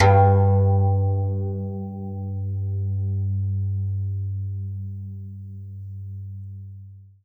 52-str03-zeng-g1.aif